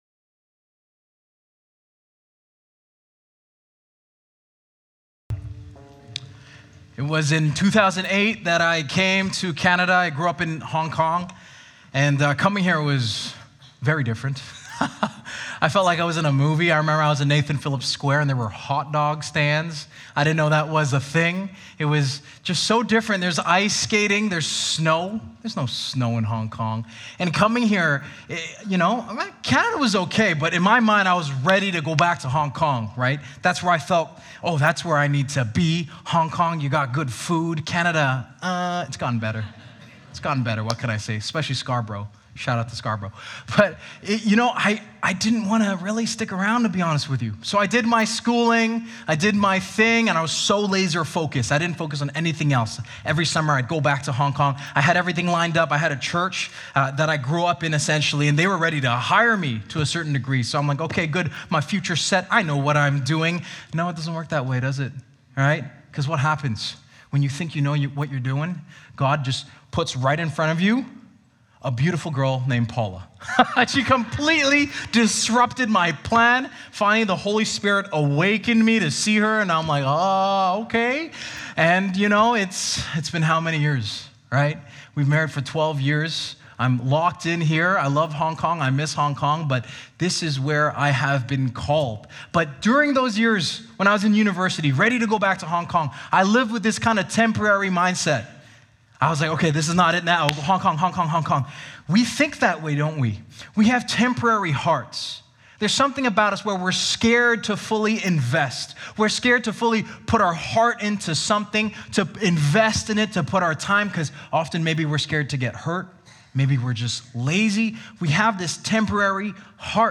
City Centre Church - Mississauga